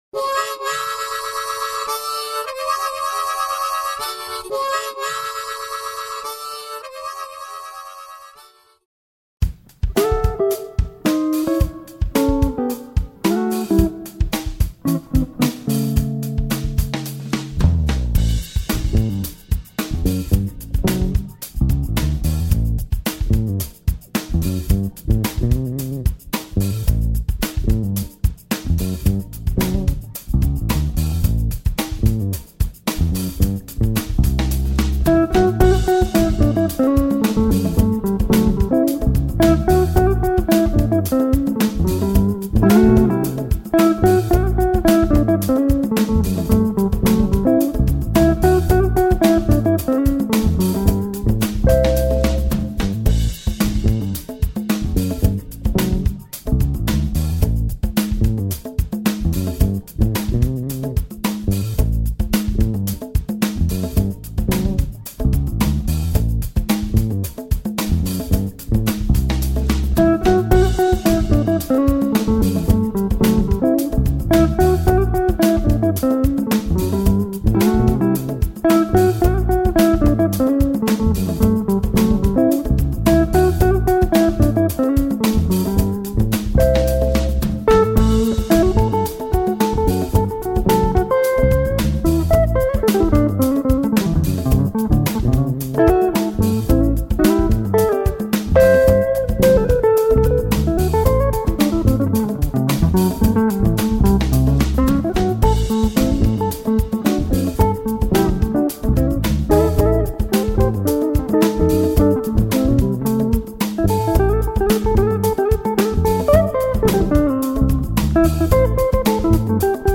funky blues licks
groove-based trio original
Low Down ‘n Funky Licks
Guitar Bass Lesson
including the intro, head, guitar, and bass solo,